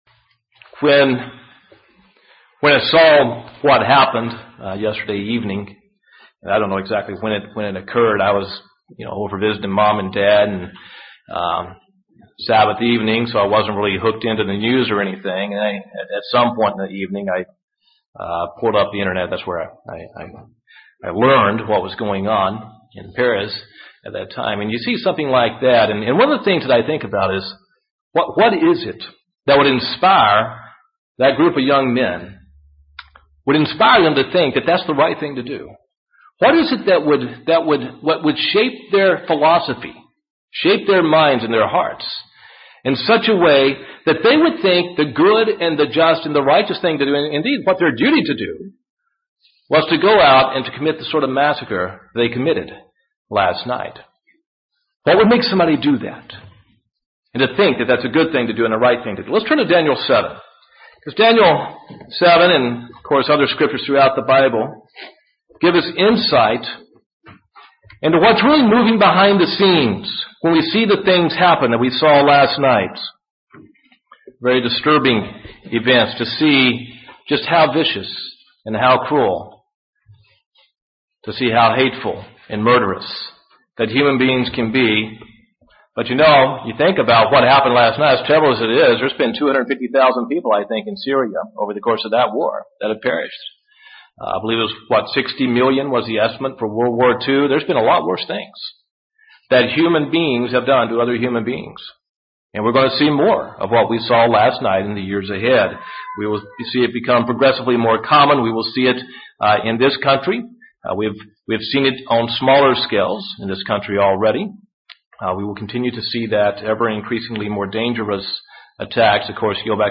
Given in Huntsville, AL